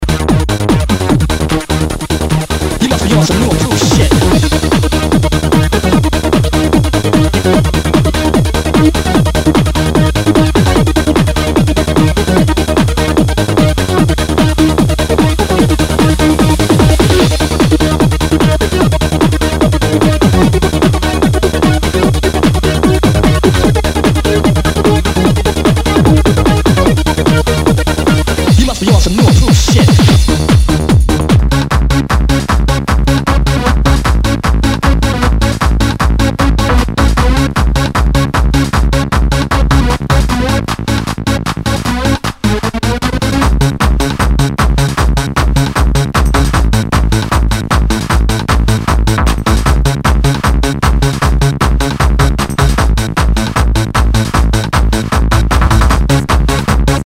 HOUSE/TECHNO/ELECTRO
ナイス！ハード・ハウス！